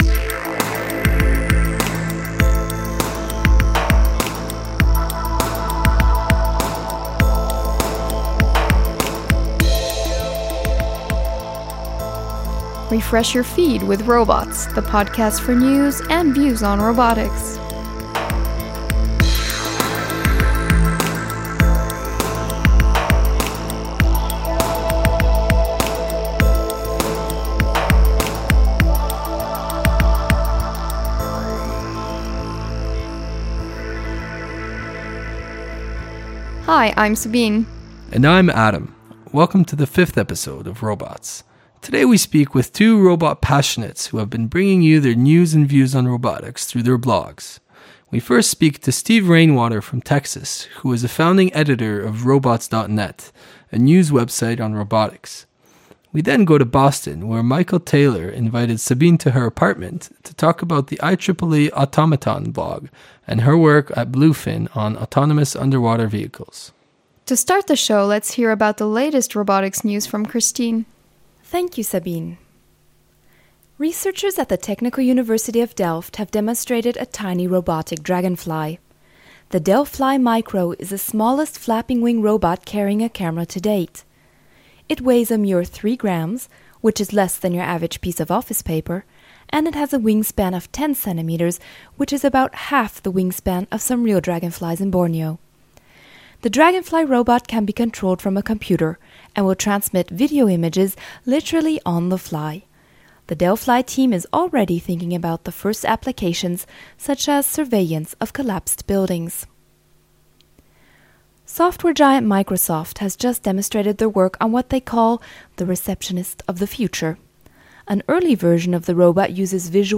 In this week’s episode we speak with two robot passionates who have been bringing you news and views on robotics through their blogs.